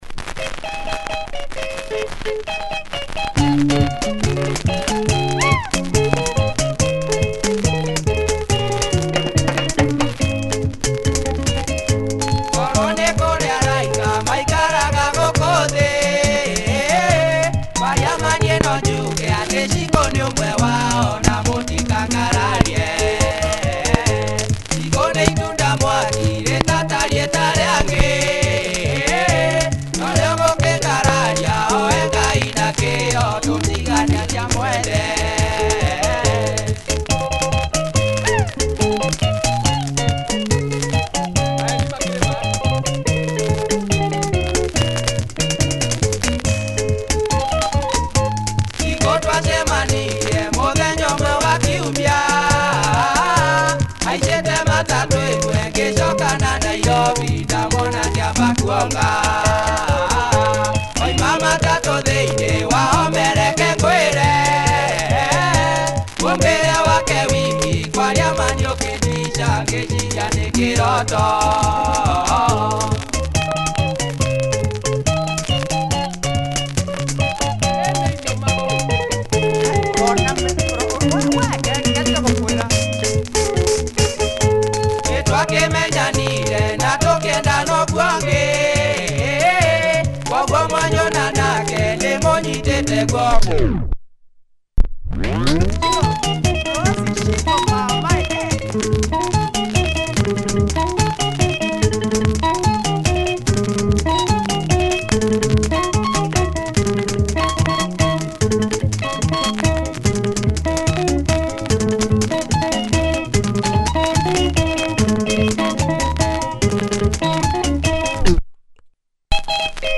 Kikuyu benga